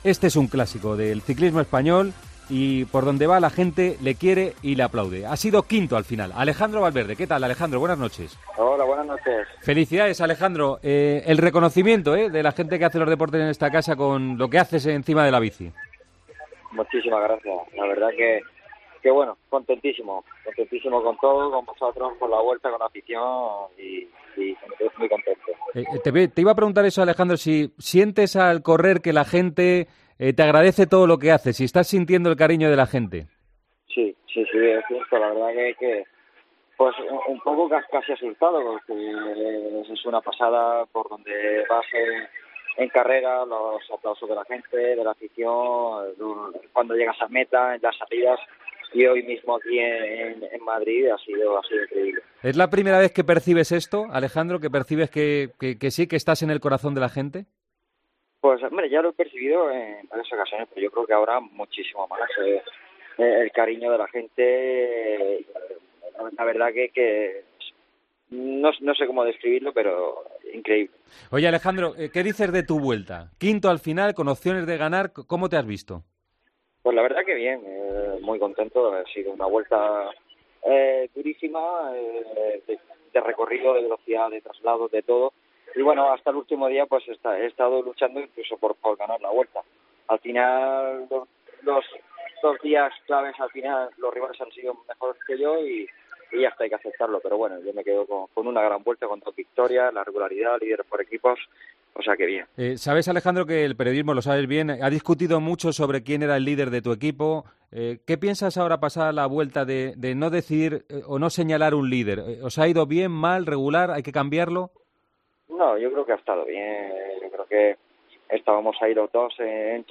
Ahora percibo muchísimo más el cariño de la gente, no sé cómo describirlo, para mí es increíble", dijo Valverde este domingo en Tiempo de Juego.